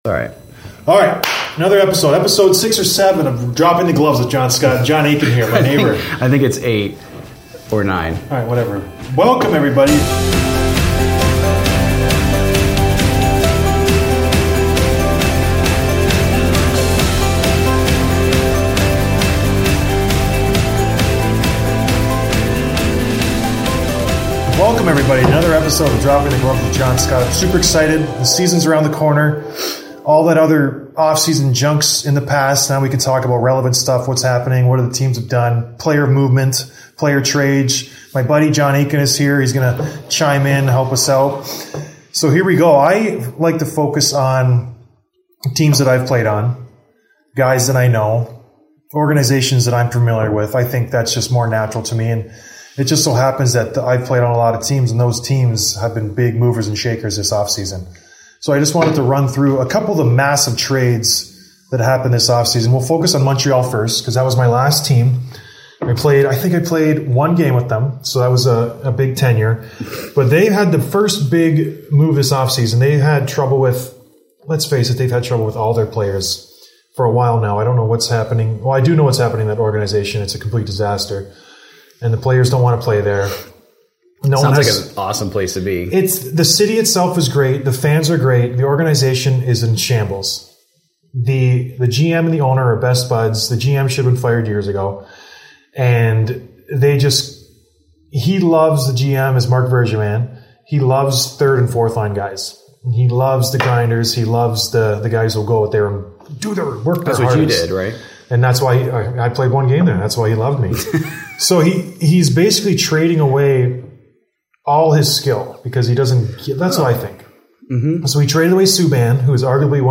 at Web Canopy Studio to talk about all my predictions for this year's NHL season